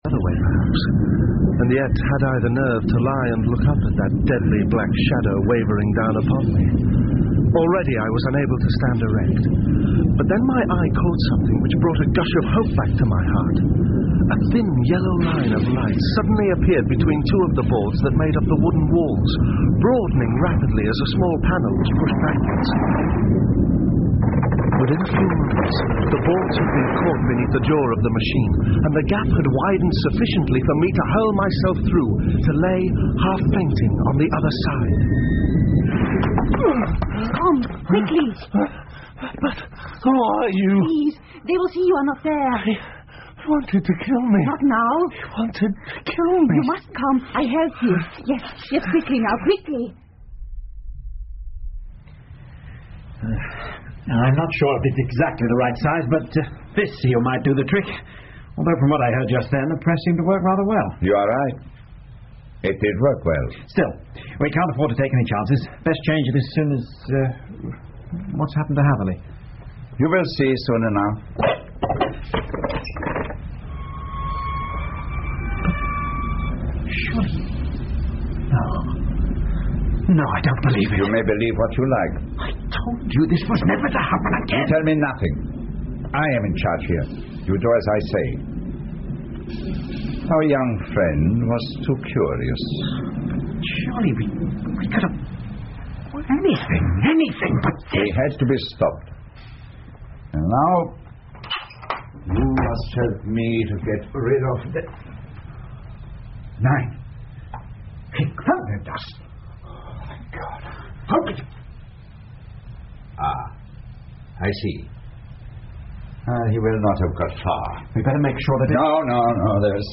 福尔摩斯广播剧 The Engineer's Thumb 6 听力文件下载—在线英语听力室